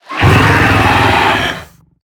Sfx_creature_chelicerate_roar_enter_01.ogg